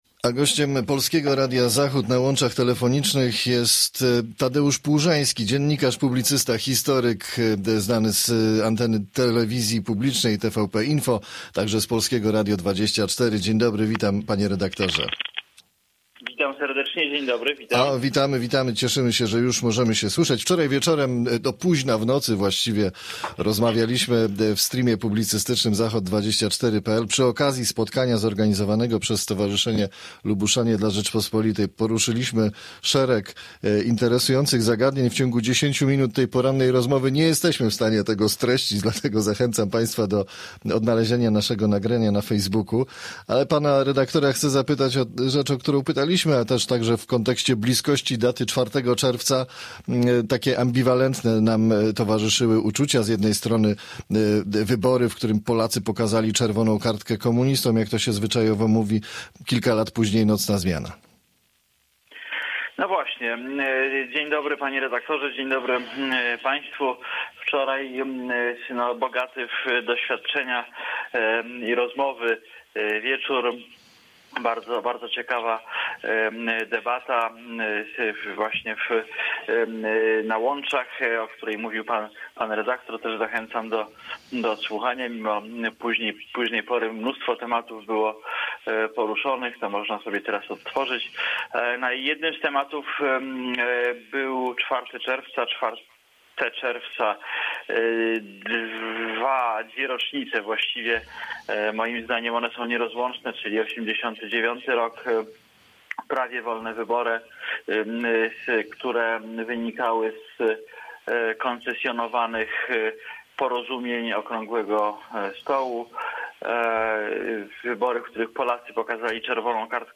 Z dziennikarzem, publicystą, historykiem rozmawia